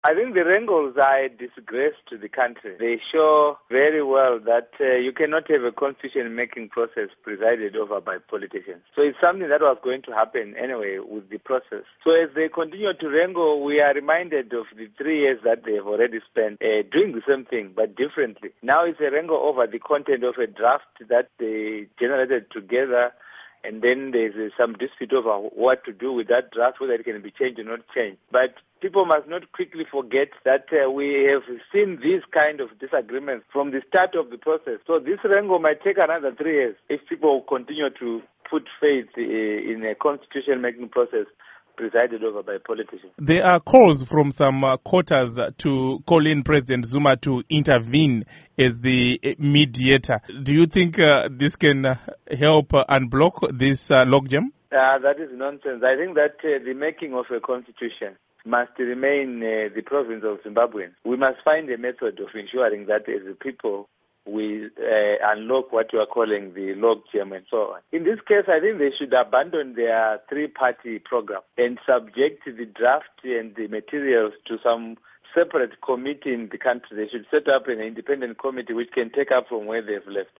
Interview With Lovemore Madhuku